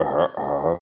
Talking Ben Ahaaha Sound Effect Free Download